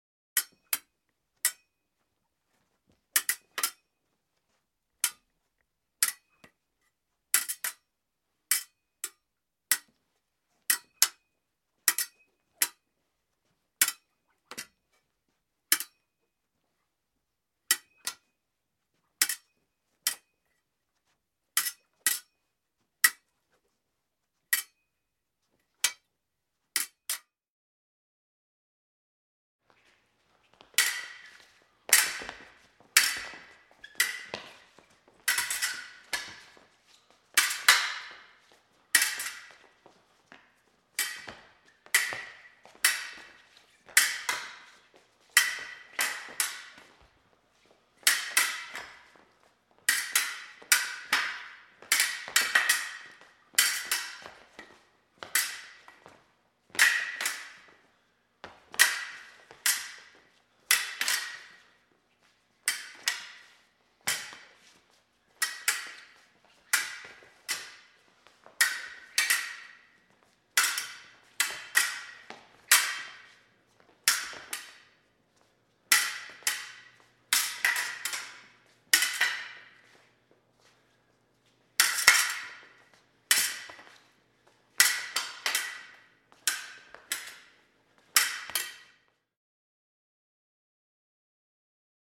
• Качество: высокое
Звуки поединка по фехтованию: схватка на стальных клинках